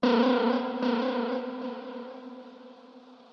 可怕的声音 " 生物鸟类歌唱
描述：让我想起了一只火鸡。
用原声吉他和各种延迟效果制作而成。